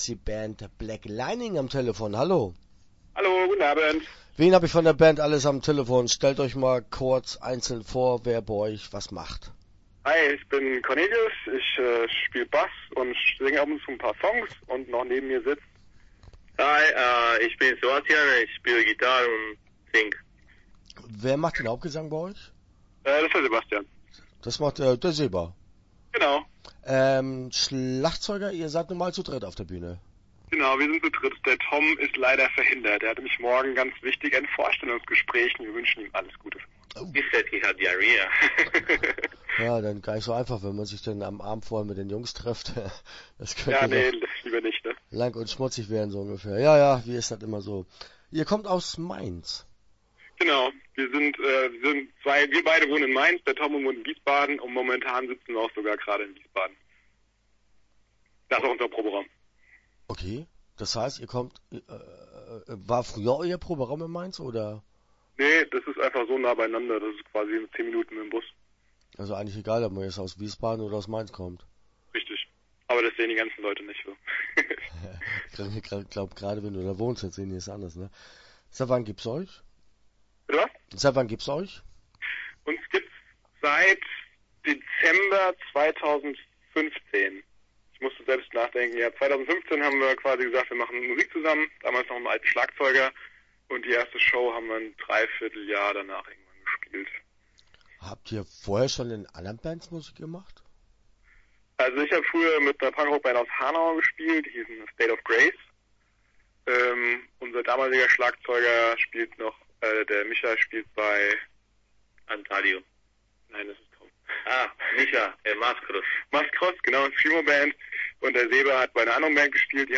Start » Interviews » Black Lining